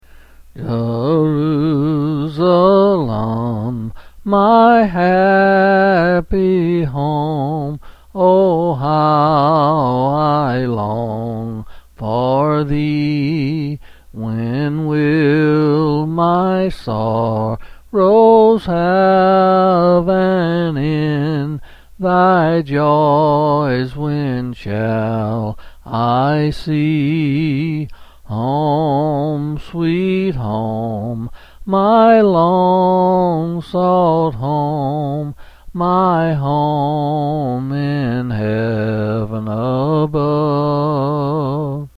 Quill Selected Hymn
C. M.